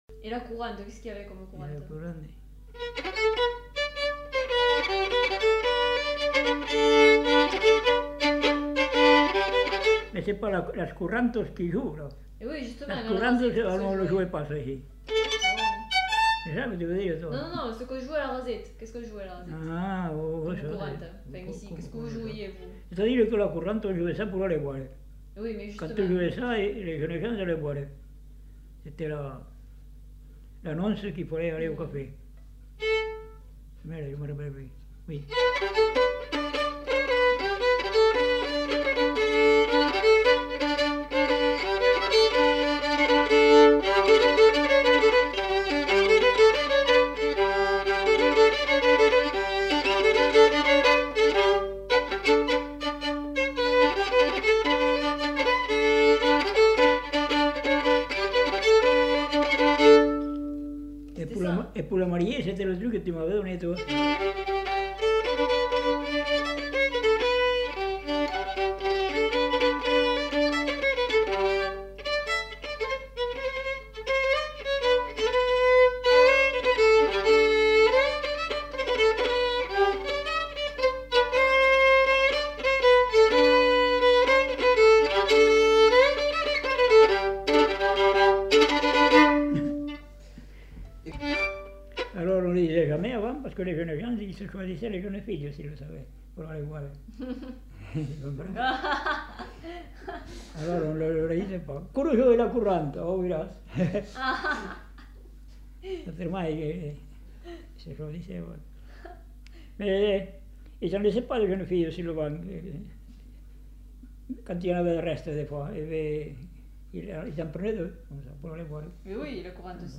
Courante
Aire culturelle : Lomagne
Lieu : Garganvillar
Genre : morceau instrumental
Instrument de musique : violon
Danse : courante